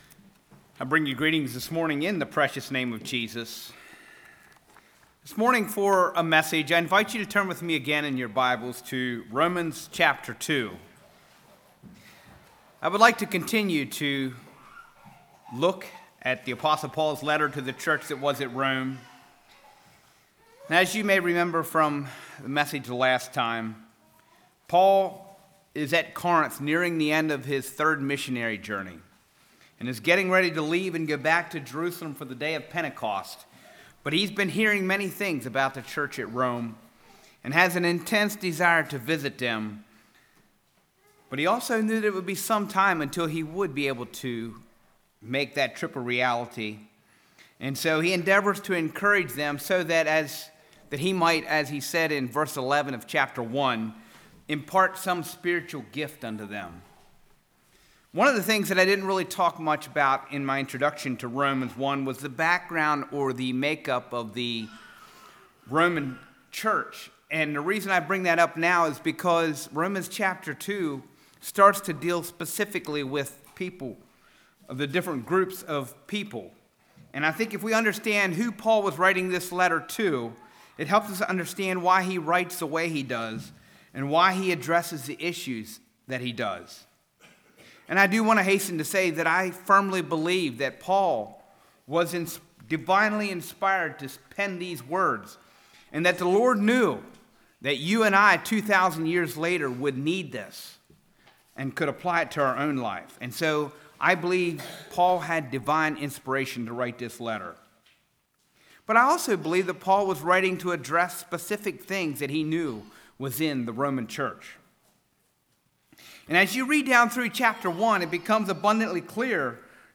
Play Now Download to Device Without Excuse Congregation: Keysville Speaker